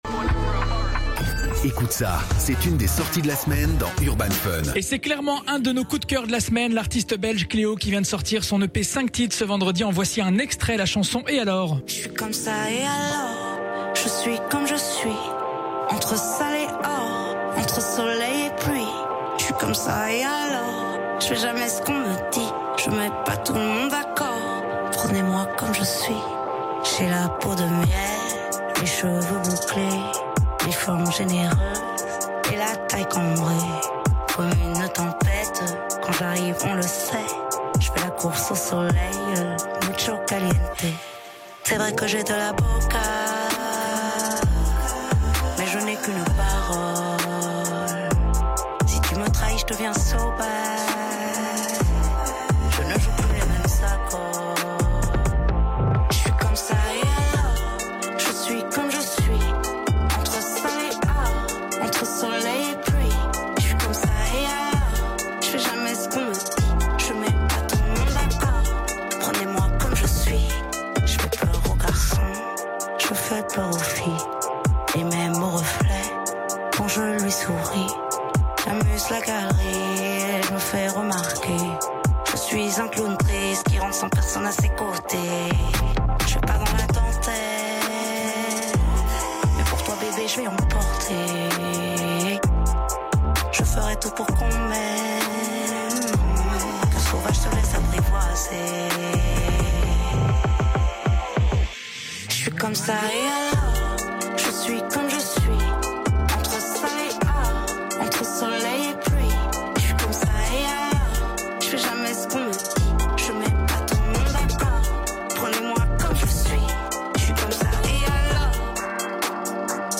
Musique & culture